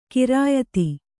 ♪ kirāyati